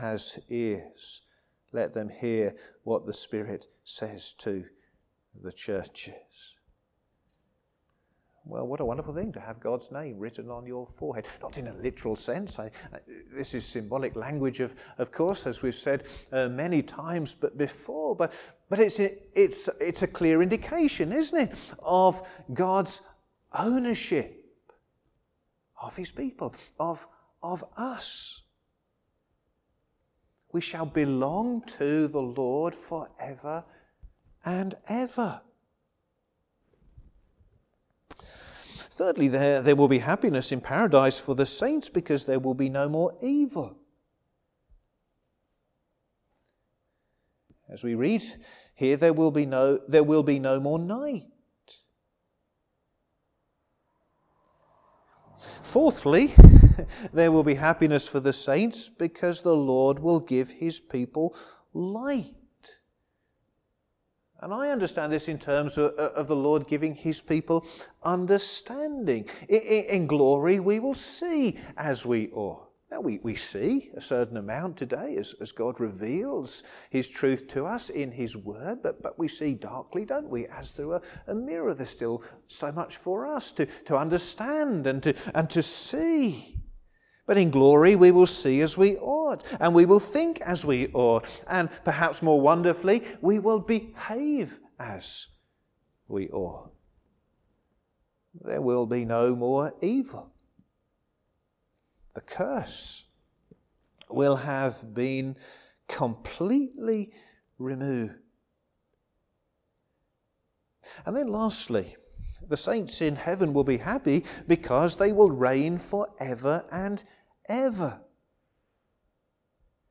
Sermons
Service Evening